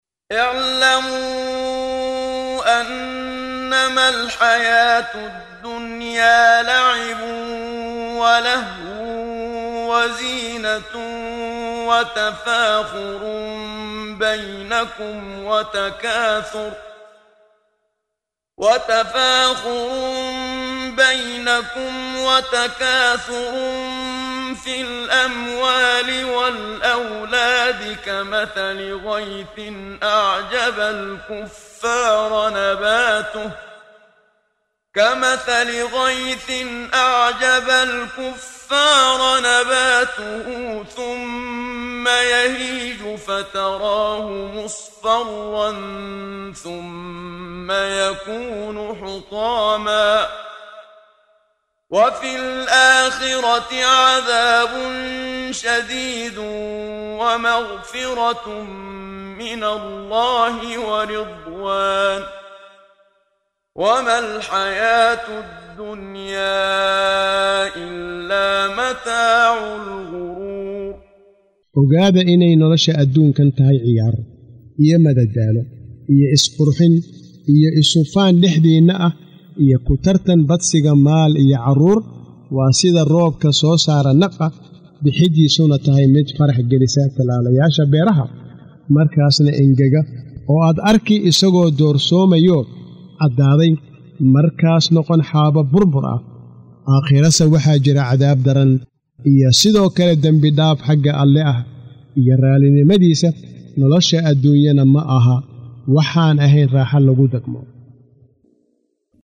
Waa Akhrin Codeed Af Soomaali ah ee Macaanida Suuradda Al-Xadiid ( Birta ) oo u kala Qaybsan Aayado ahaan ayna la Socoto Akhrinta Qaariga Sheekh Muxammad Siddiiq Al-Manshaawi.